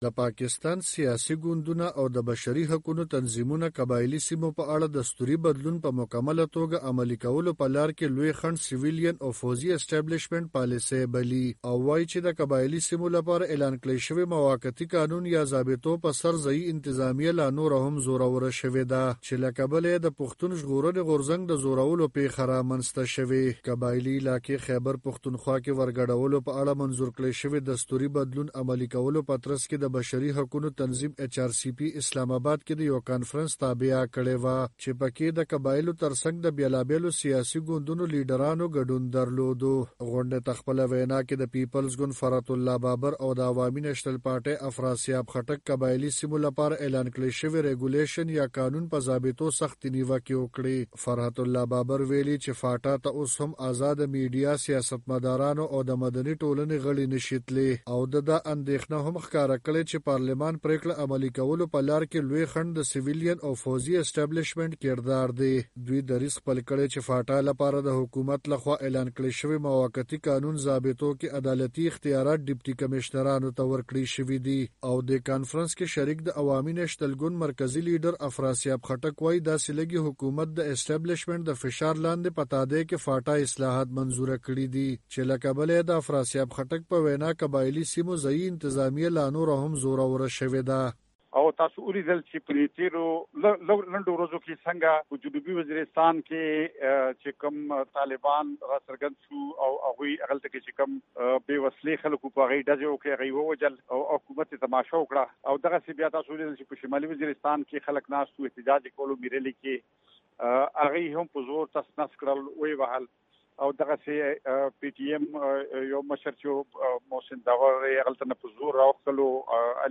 په اسلام اباد کې داېچ ار سي پي دغه غونډه کې شوې بحث په اړه نور حال د وي او ای خبریال په دې رپورټ کې واورئ.